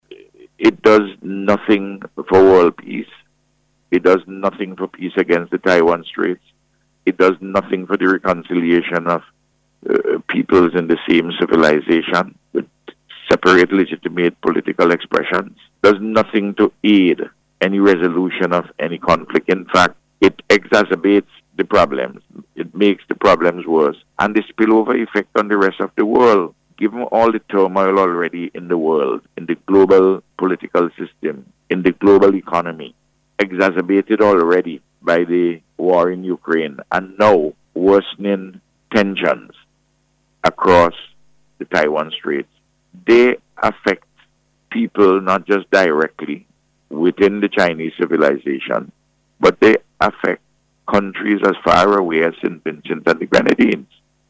The call was made by Prime Minister Dr. Ralph Gonsalves, during NBC’s Face to Face programme this morning.